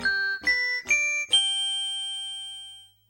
Slowed down to 25% speed in Tenacity
Category:Sound effects media files